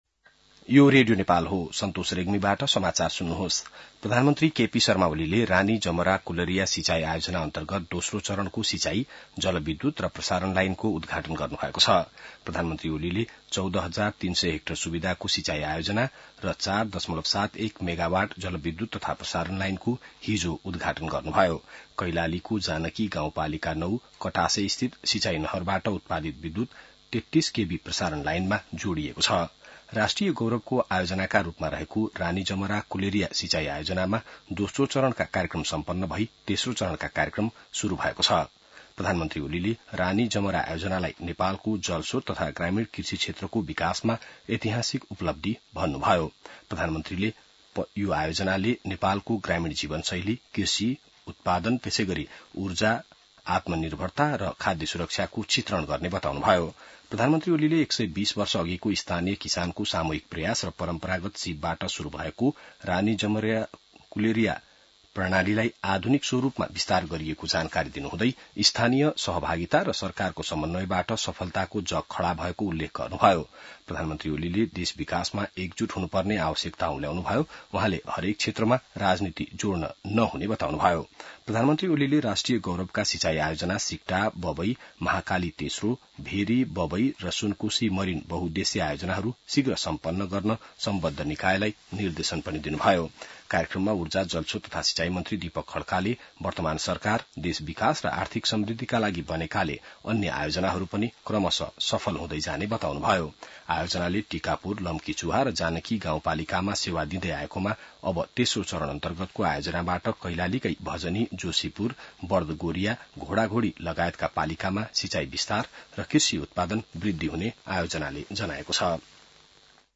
बिहान ६ बजेको नेपाली समाचार : १४ साउन , २०८२